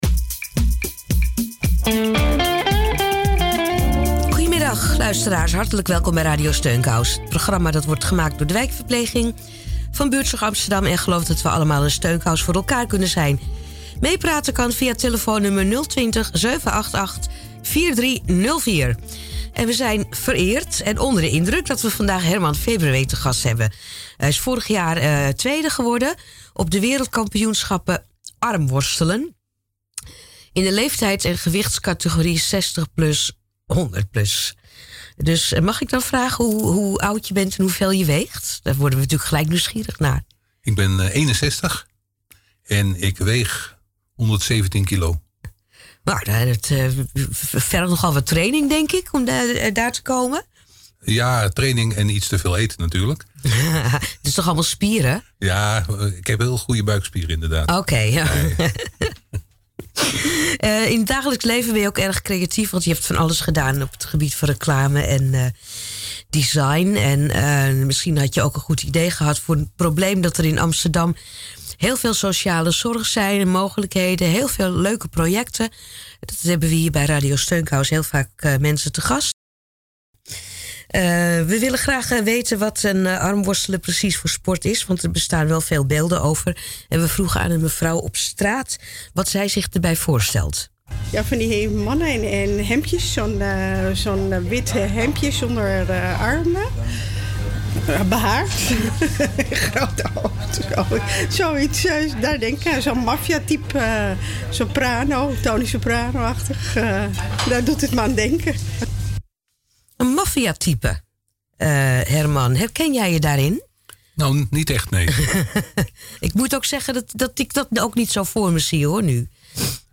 Armworstelen bij de lokale radio - Amsterdam Armwrestling
uitzending_Radio_Steunkous_op_Salto.mp3